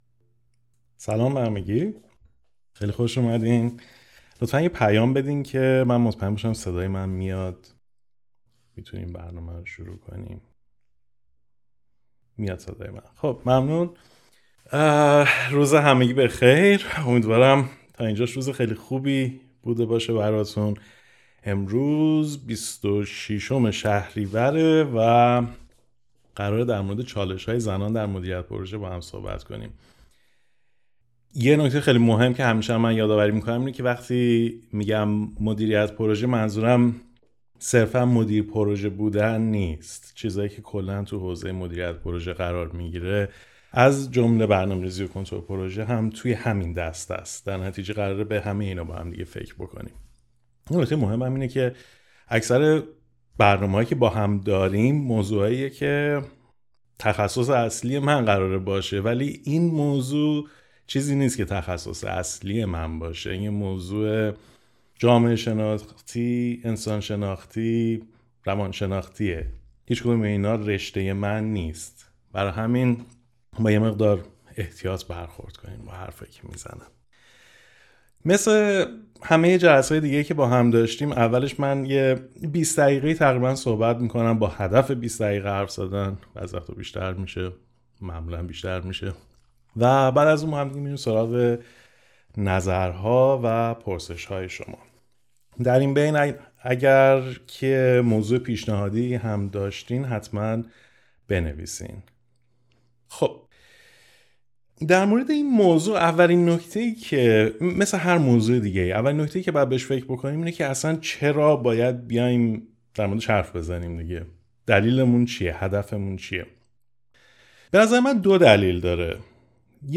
مثل نشست‌های پیشین، اول حدود ۲۰ دقیقه صحبت می‌کنم و توش قصد دارم یک چالش خاص که برای مردان هم وجود داره، ولی برای زنان دردسرسازتر می‌شه رو به طور ریشه‌ای توضیح بدم و ترفندهایی که به تجربه تا حدی نتیجه بخش بودن رو طرح کنم. بعد از اون هم می‌ریم سراغ پرسش‌ها و نظرها، با این امید که بتونیم راه حل‌های بیشتری به کمک همدیگه پیدا کنیم.